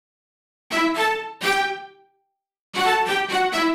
Index of /musicradar/uk-garage-samples/128bpm Lines n Loops/Synths
GA_StaccStr128E-02.wav